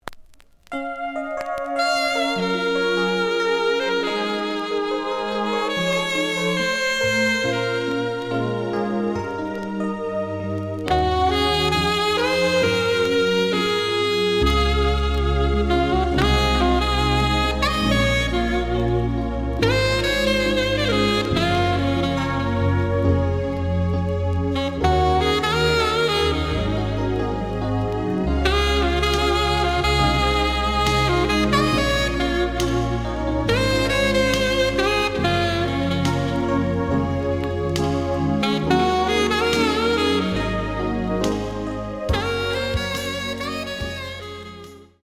(Instrumental Mix)
The audio sample is recorded from the actual item.
●Format: 7 inch
●Genre: Rock / Pop